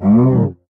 animalia_cow_death.ogg